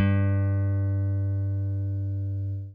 FENDERSFT AB.wav